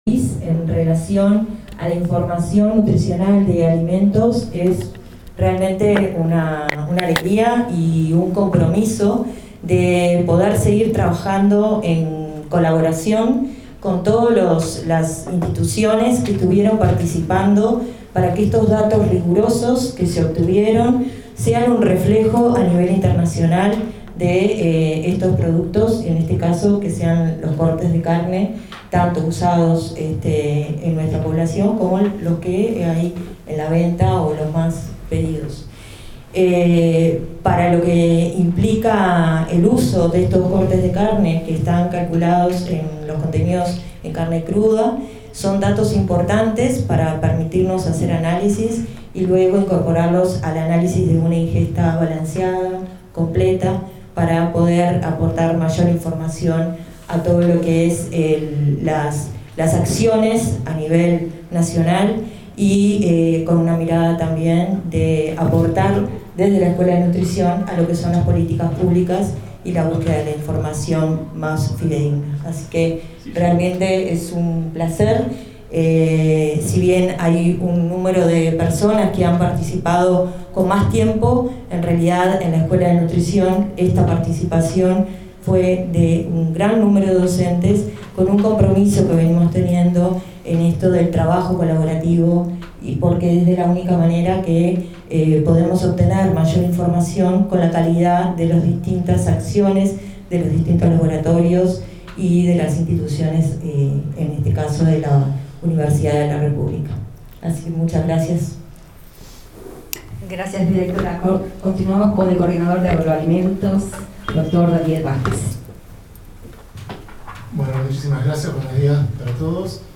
Gentileza Charrúa TV AUDIO DE LA CONFERENCIA.